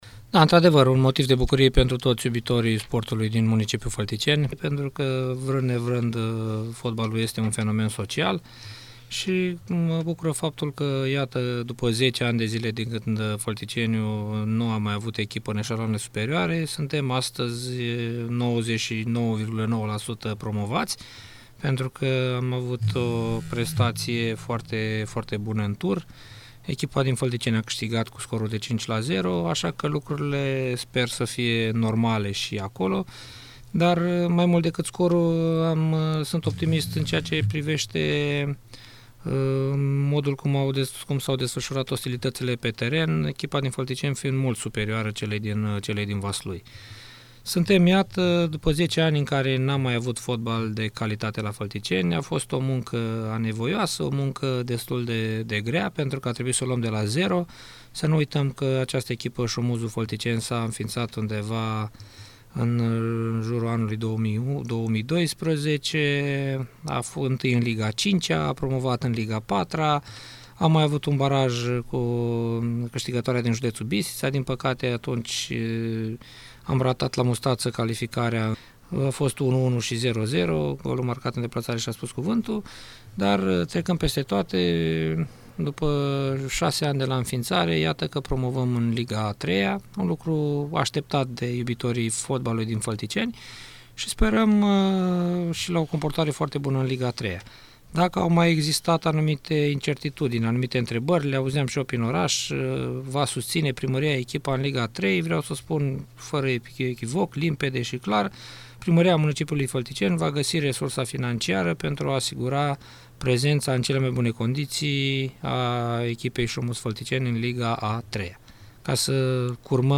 Invitat la Radio VIVA FM, primarul de Fălticeni, Cătălin Coman, a dat asigurări că municipalitatea va sprijini echipa de fotbal şi în cazul aşteptatei promovări în Liga a III-a.